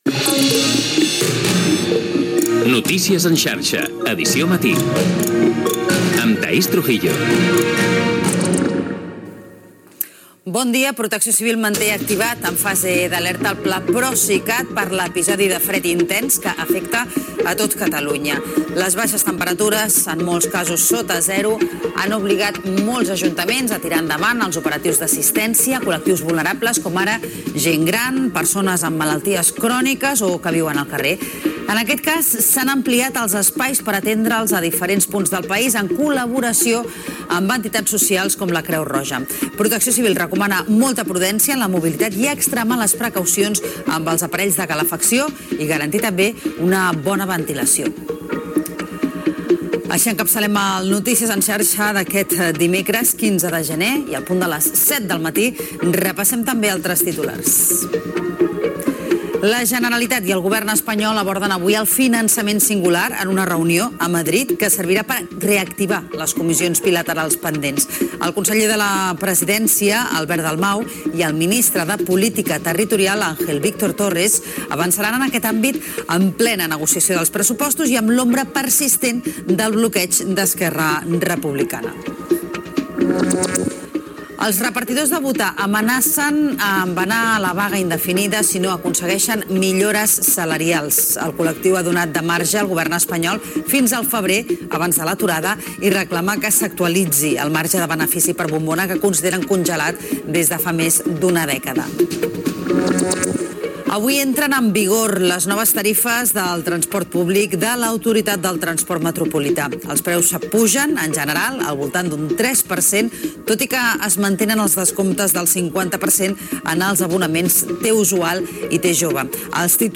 Careta del programa, alerta pel fred, data i hora, titulars, el trànsit, el temps, indicatiu del programa, temperatures baixes a Catalunya amb informació des d'Osona
Informatiu